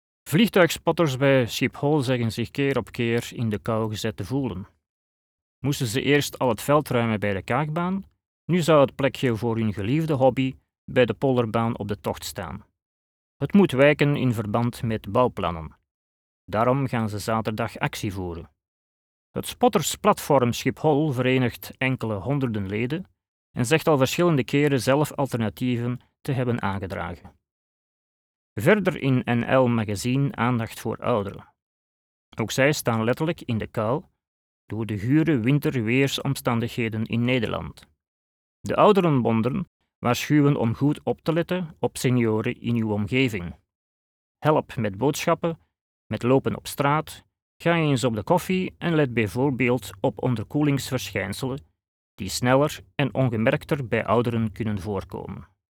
Dutch (Belgium) voiceover